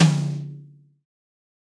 Roland.Juno.D _ Limited Edition _ Brush Kit _ Tommy.Brush.wav